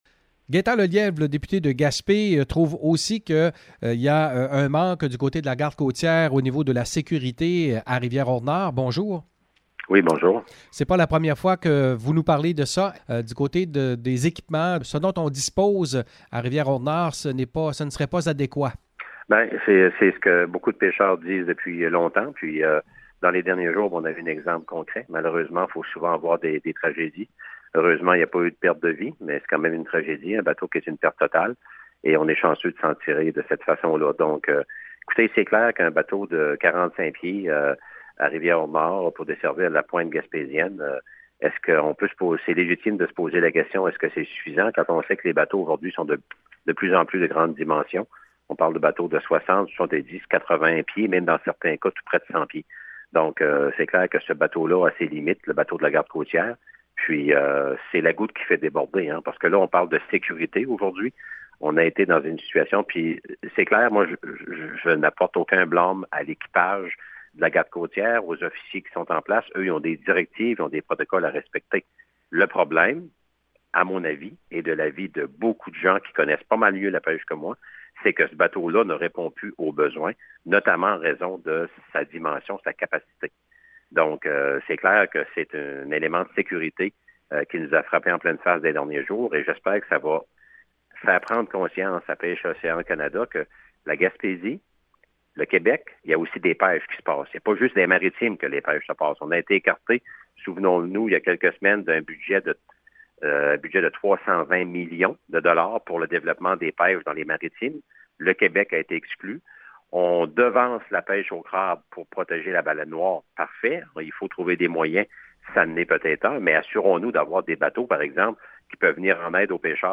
Entrevue avec le député Gaétan Lelièvre: